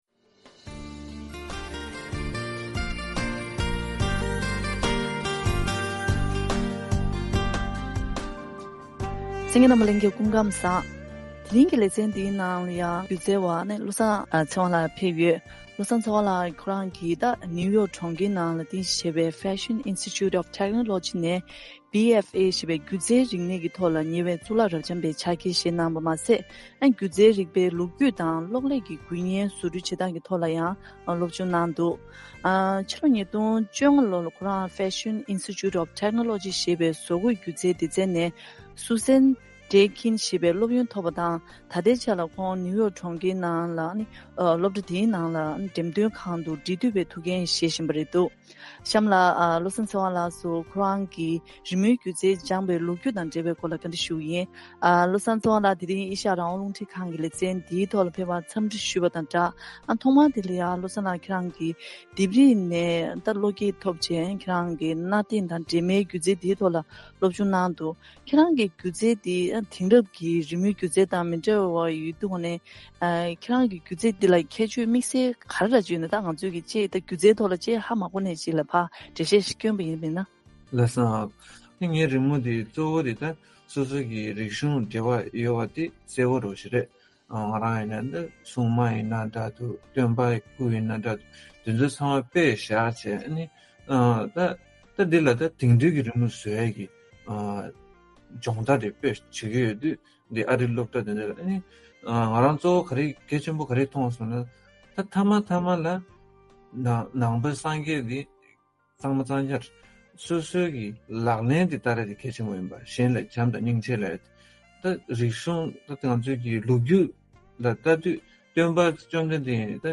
བཀའ་དྲི་ཞུས་པའི་ལས་རིམ་དེ་གསན་གནང་གི་རེད།།